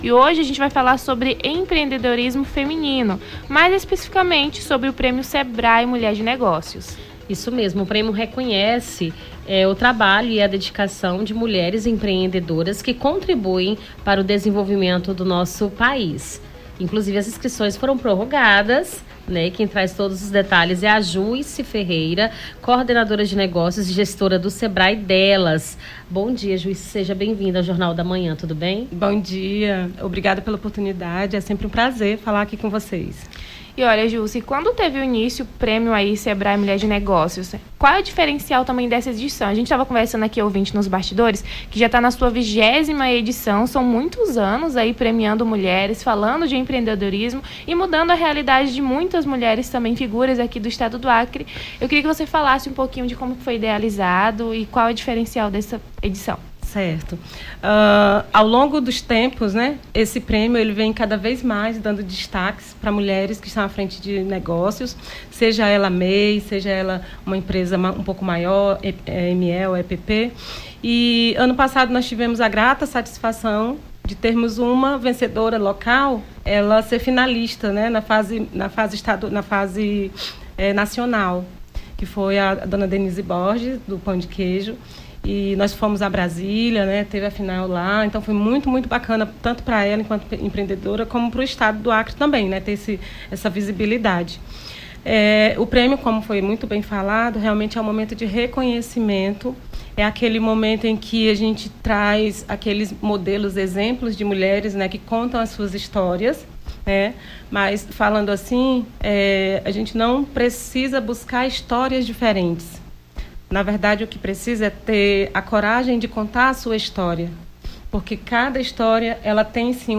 Nome do Artista - CENSURA - ENTREVISTA PRÊMIO SEBRAE MULHER DE NEGÓCIOS (07-08-24).mp3